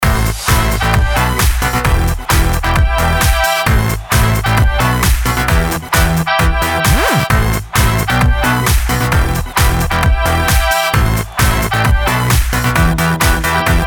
короткий ремикс